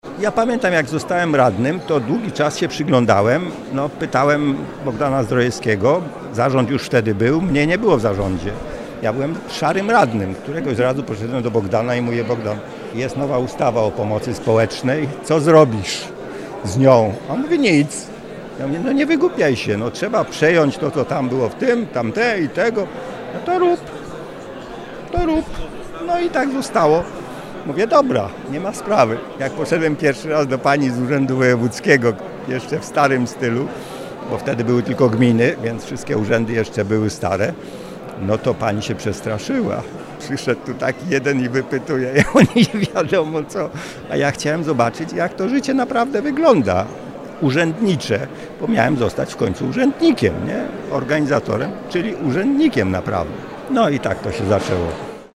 We wrocławskim Ratuszu odbyła się promocja książki pt. „Urządzamy Wrocław na nowo. Rada Miejska Wrocławia 1990-1994”.